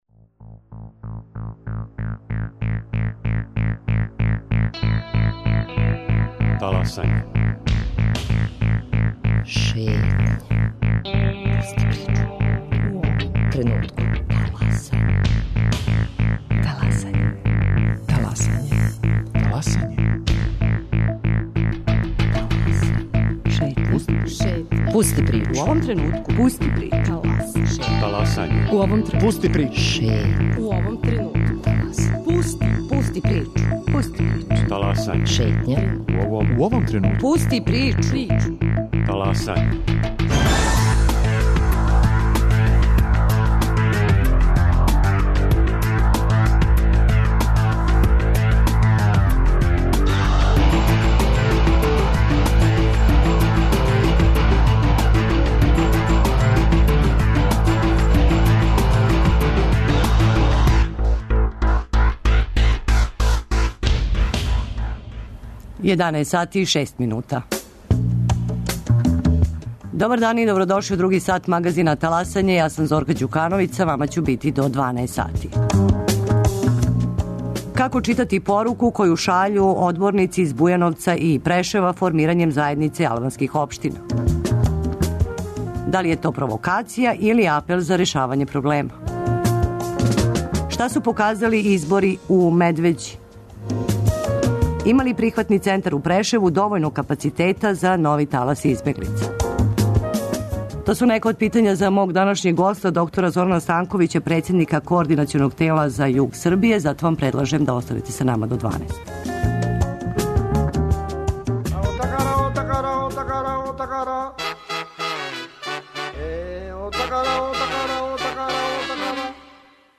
Гост Таласања др Зоран Станковић, председника Координационог тела за југ Србије.